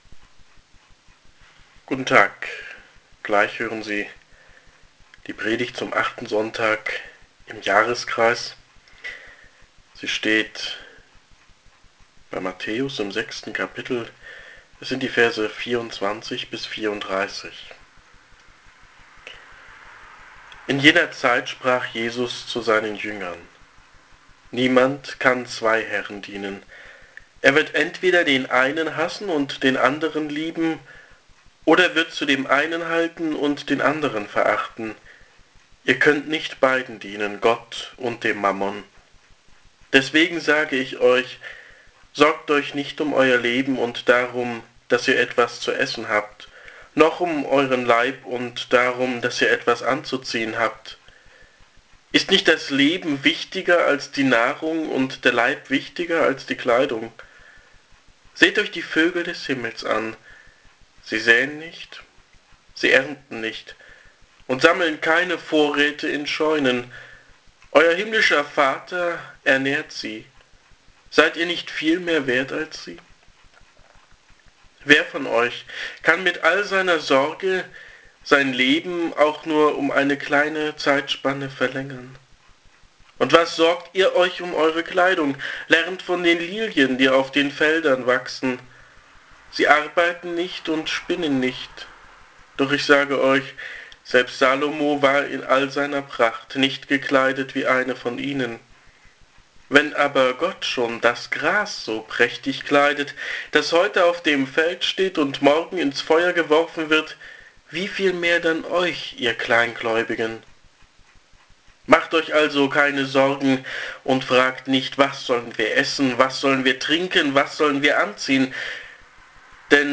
Hier ist die erste Predigt.
Gehalten am 27. Februar in der katholischen Pfarreikirche zu Wittenberg.
Das Evangelium (Mt 6. 24-23) gibts vornweg zum hören.